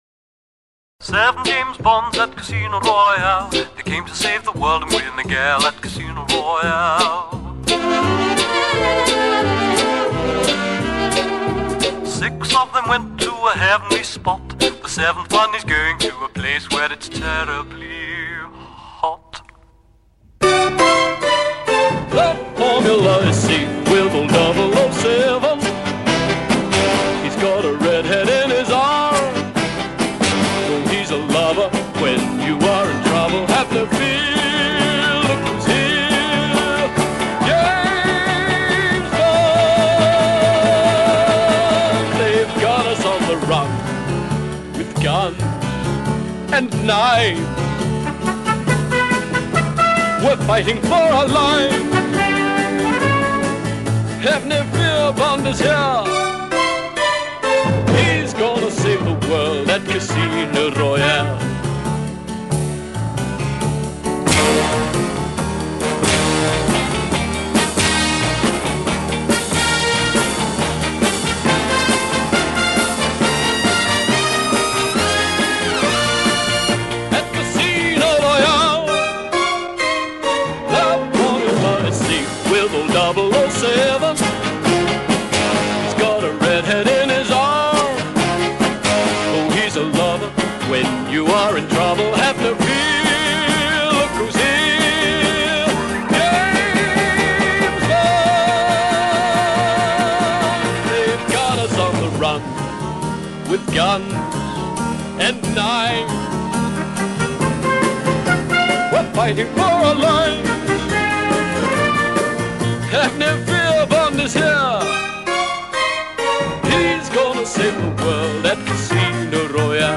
US • Genre: Soundtrack